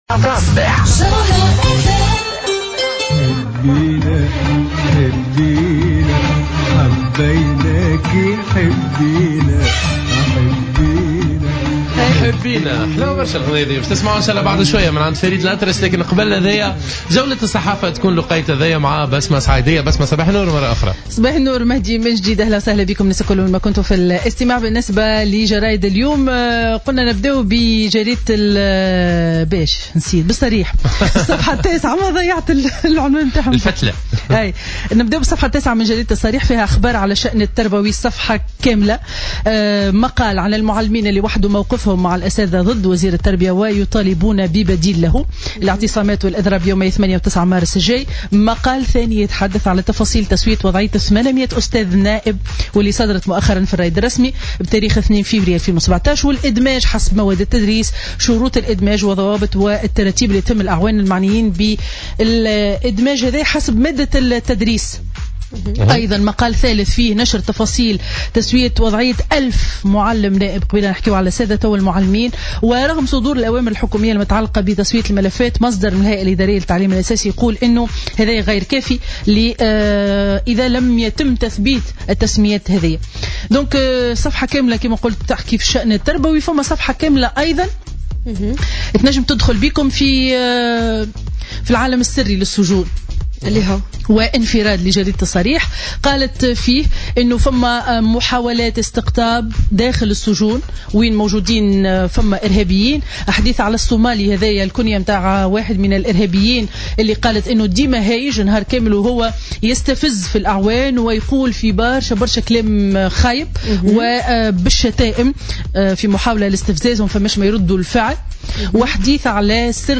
Revue de presse du vendredi 10 février 2017